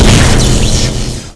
sounds / weapons / tesla / shoot.wav
shoot.wav